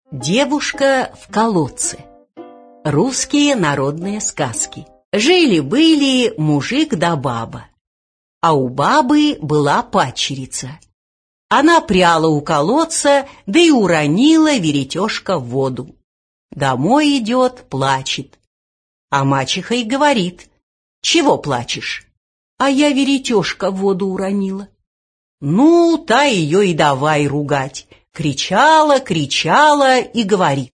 Аудиокнига Девушка в колодце | Библиотека аудиокниг